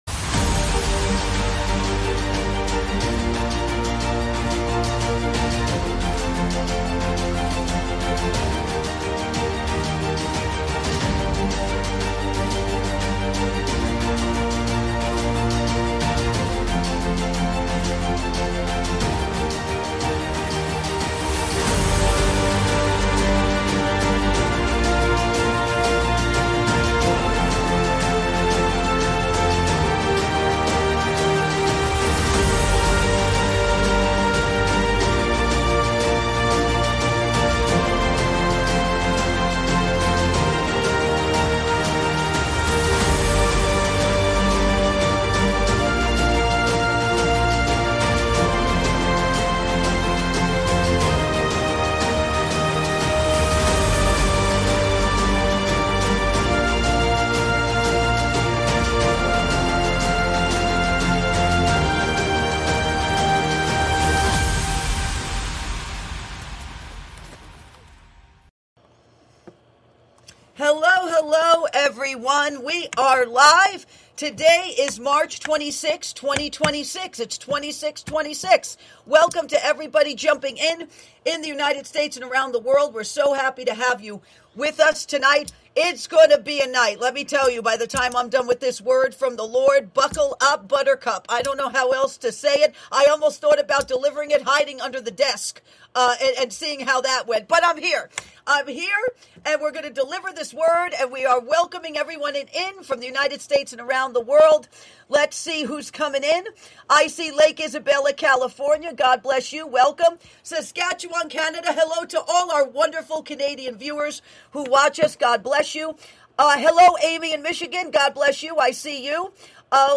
Talk Show Episode, Audio Podcast, Ark of Grace and On the Brink of Passover… This Powerful Word From the Lord Is For YOU on , show guests , about On the Brink of Passover,This Powerful Word From the Lord Is For YOU,Prophetic Outlook 2026: The Shaking of Nations and the Call to Holiness,Eve of Passover,season of great turbulence,exposure of political secrets,significant shifts in global power structures,a divine repositioning of the faithful within the Church and government,Political Exposures and Administrative Transitions,time of great uncovering, categorized as News,Paranormal,Politics & Government,Religion,Christianity,Self Help,Society and Culture,Spiritual,Theory & Conspiracy